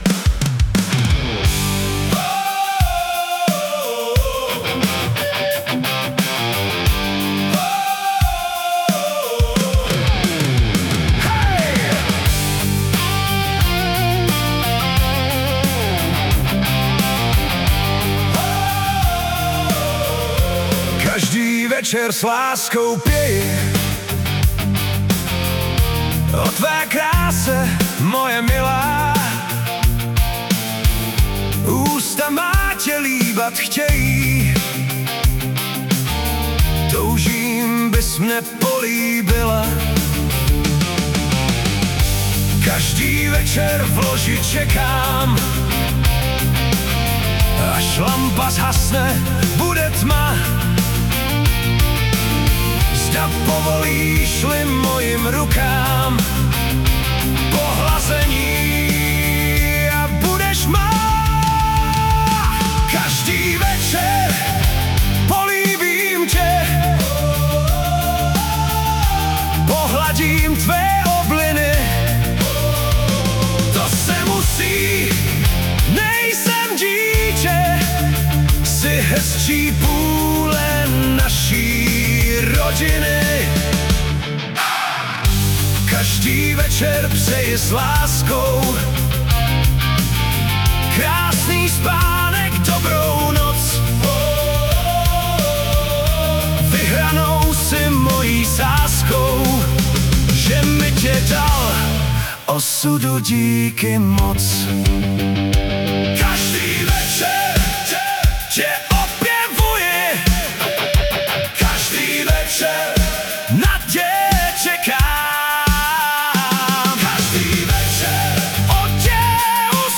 hudba, zpěv: AI
bigbít jak má bejt - pro její ústa :)*